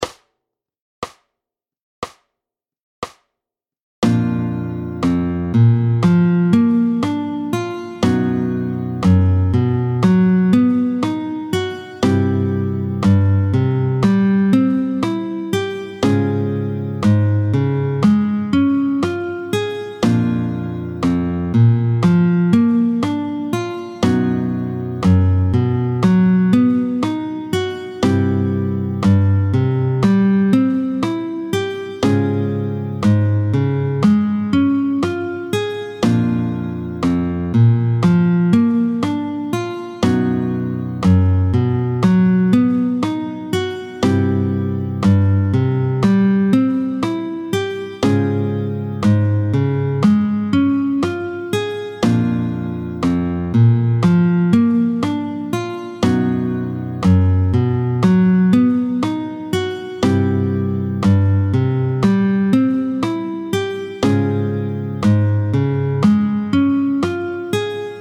28-02 Les barrés à base de « La », tempo 60